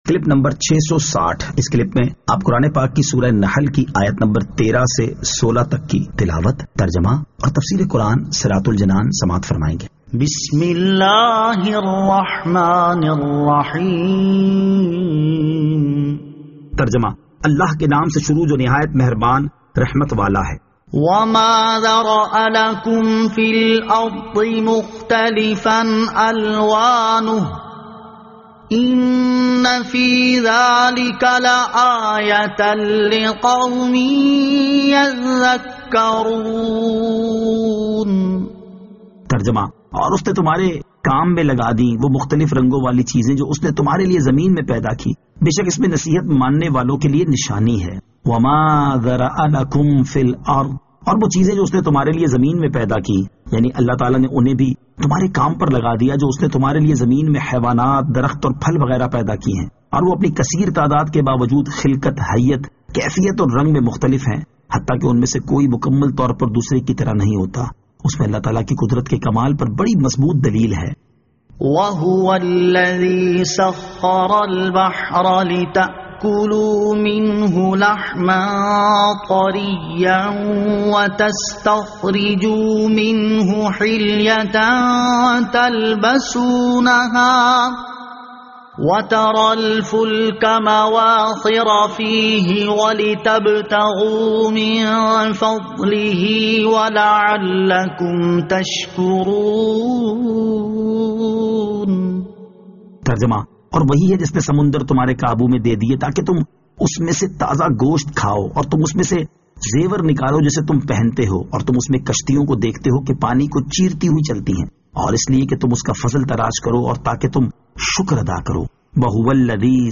Surah An-Nahl Ayat 13 To 16 Tilawat , Tarjama , Tafseer